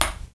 Index of /90_sSampleCDs/Roland L-CD701/PRC_FX Perc 1/PRC_Ping Pong